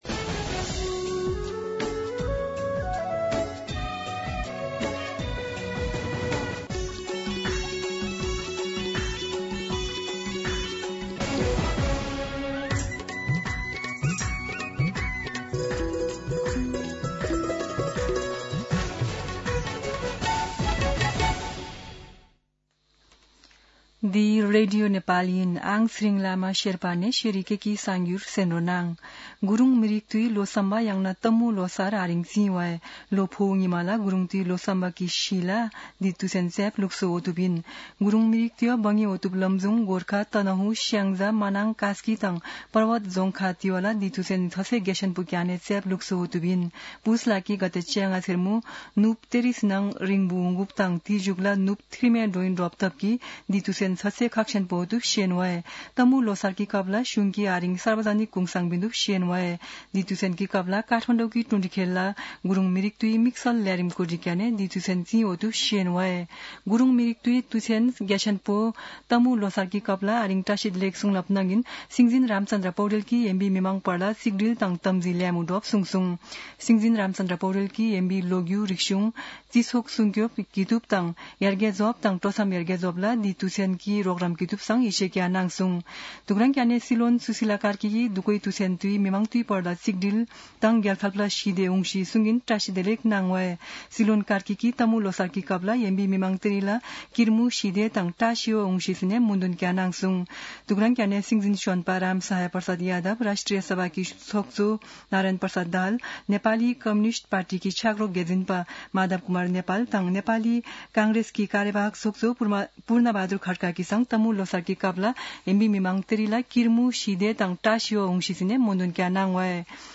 शेर्पा भाषाको समाचार : १५ पुष , २०८२
Sherpa-News-15.mp3